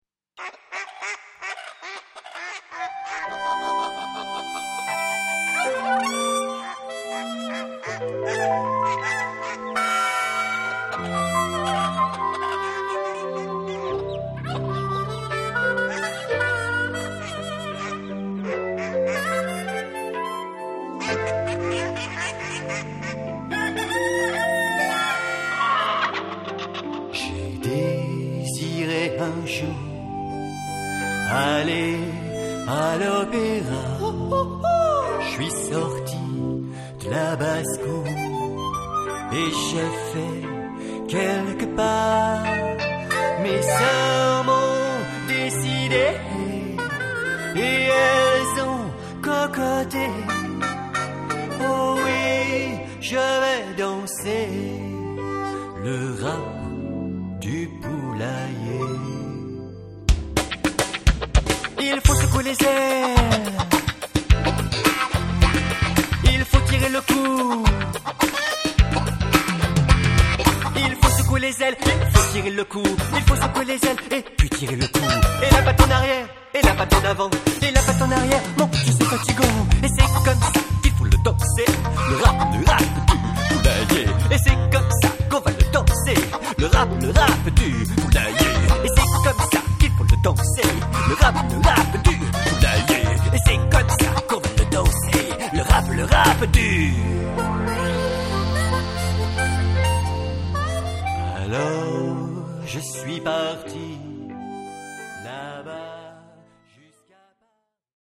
Audio : extraits des chansons.